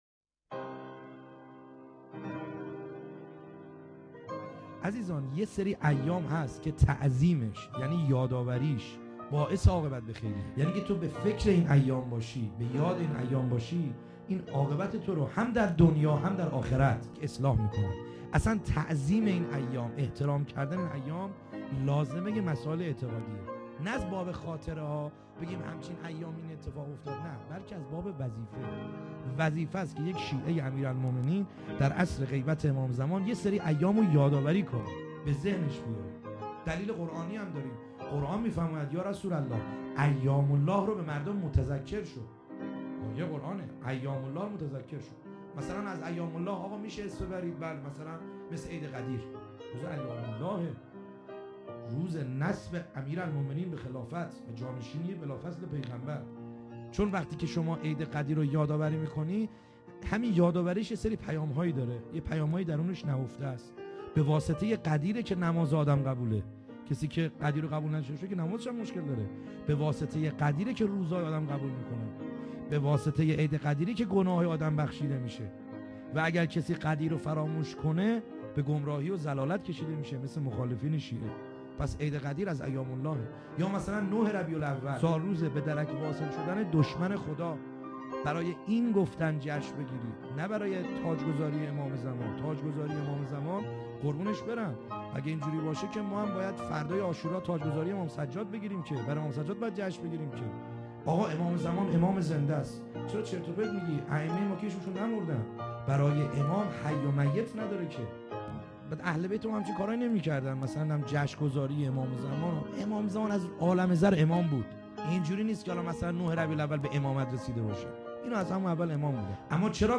خیمه گاه - بیرق معظم محبین حضرت صاحب الزمان(عج) - سخنرانی | ایام محسنیه